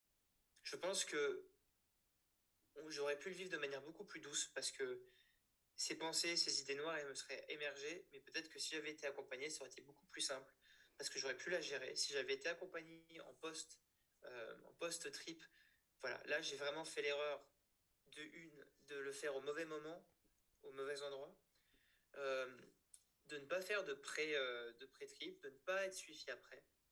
AUDIO : Un témoin anonyme analyse, 4 ans plus tard, sur son bad trip sous LSD
Temoignage-anonyme-bad-trip-LSD.mp3